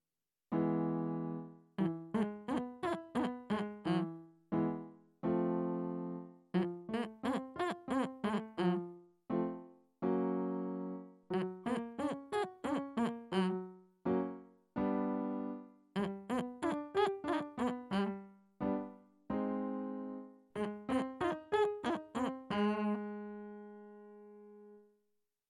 • 地声から裏声をまたぐような音階で練習する
• 少し鋭い喉頭の位置が高いハミングをスタッカートで発声する
• なるべく上から下まで少しジリジリした音色と音量が変わらないように発声する
音量注意！